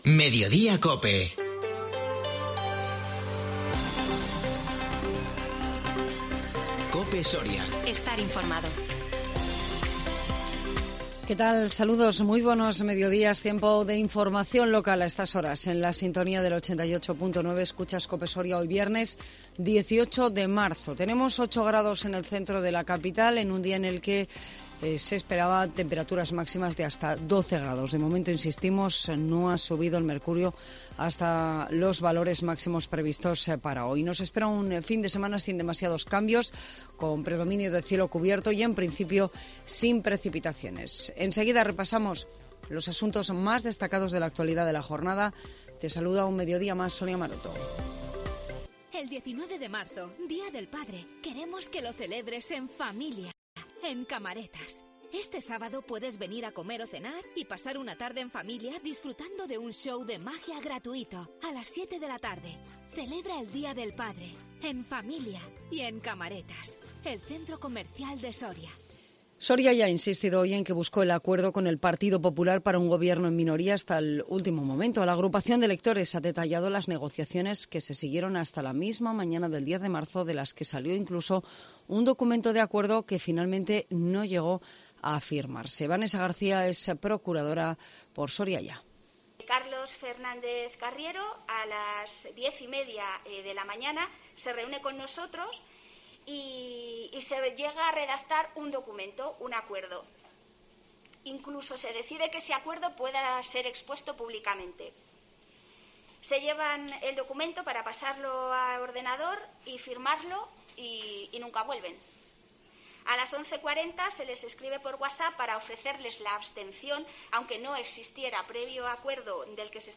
INFORMATIVO MEDIODÍA COPE SORIA 18 MARZO 2022